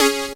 SYNTH GENERAL-1 0002.wav